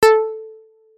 notification_sounds